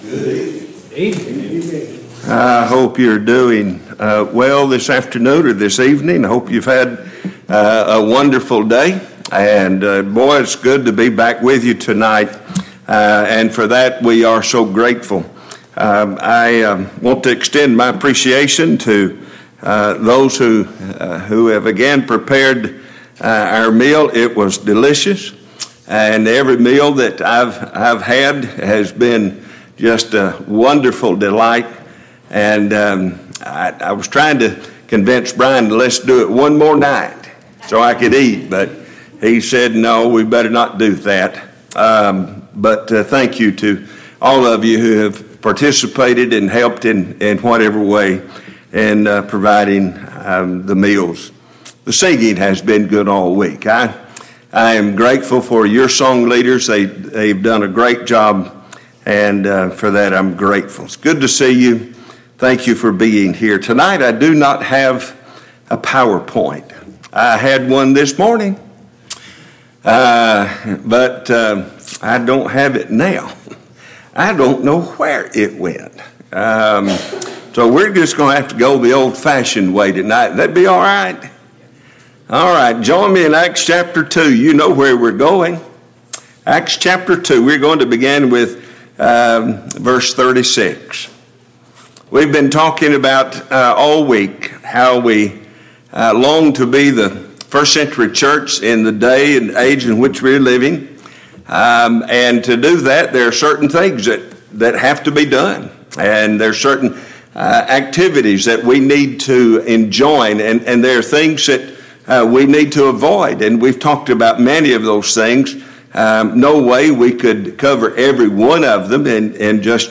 Passage: Acts 2:36-47 Service Type: Gospel Meeting « 5. 2022 Fall Gospel Meeting Lesson 5 6.